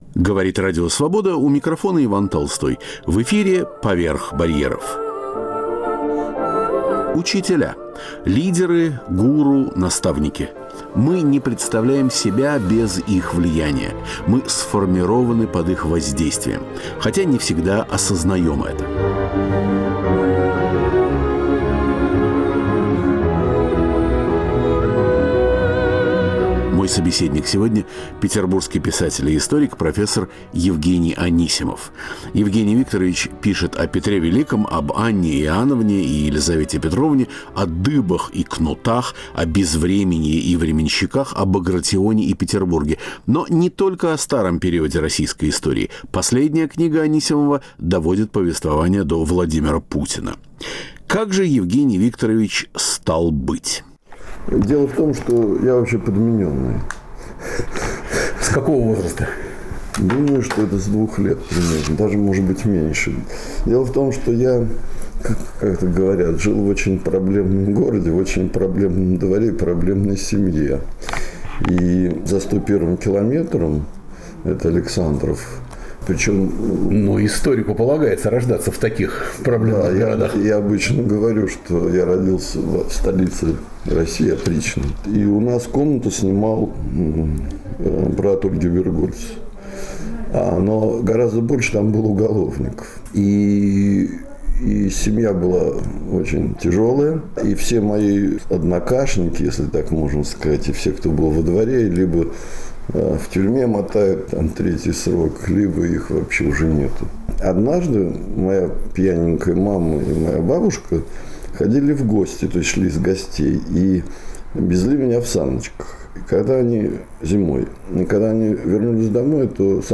Петербургский историк Евгений Анисимов - о своей семье, учении, друзьях и книгах. Беседа входит в цикл "Учителя"